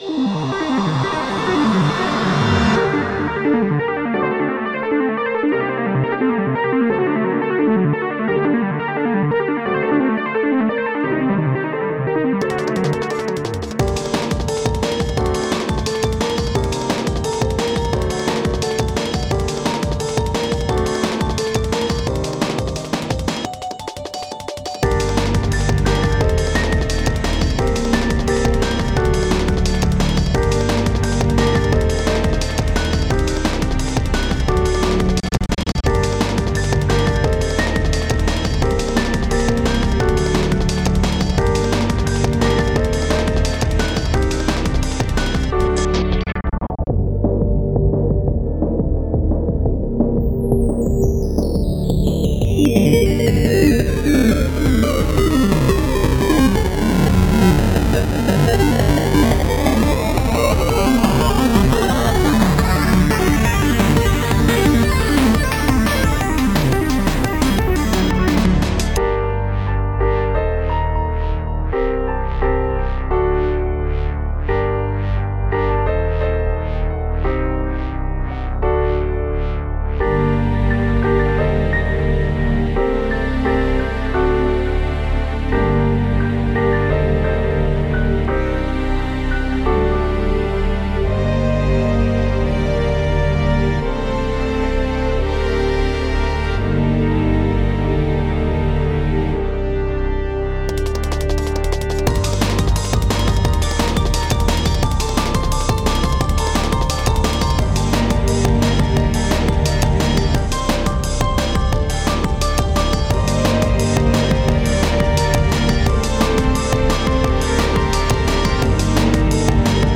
Breaching Atlantis [ DnB Soundtrack ]
drum n bass